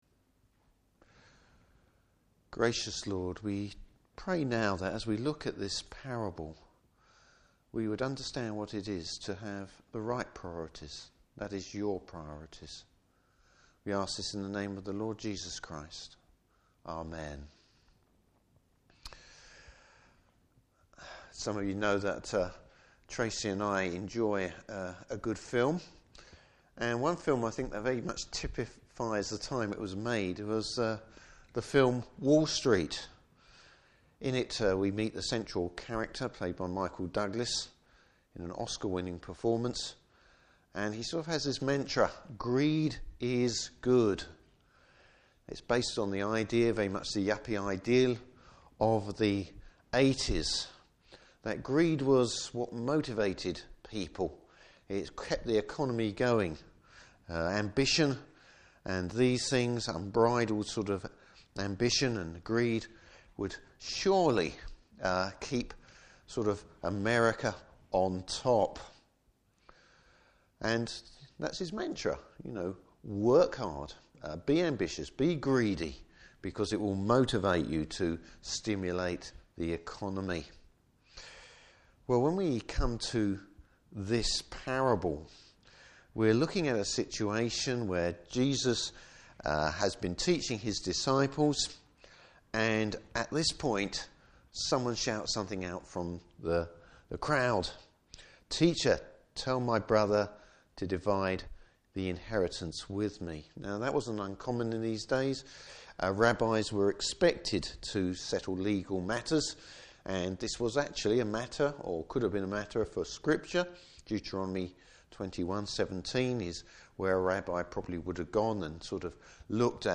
Service Type: Morning Service Bible Text: Luke 12:13-21.